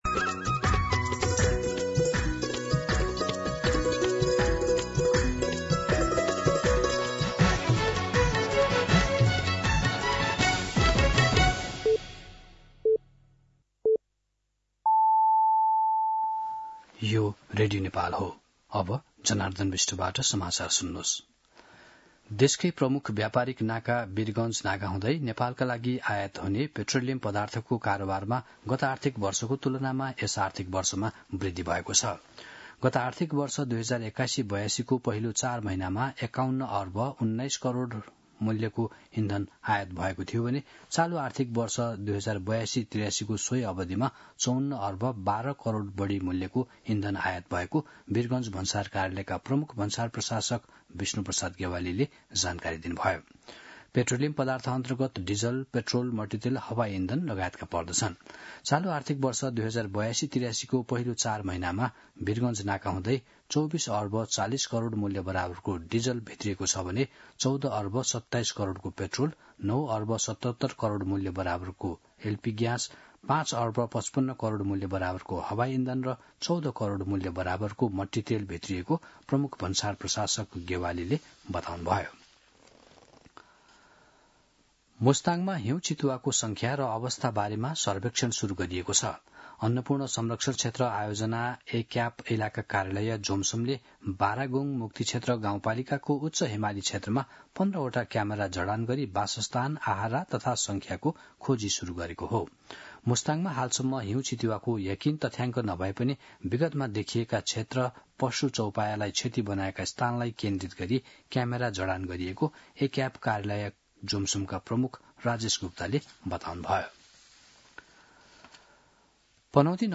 दिउँसो १ बजेको नेपाली समाचार : १२ मंसिर , २०८२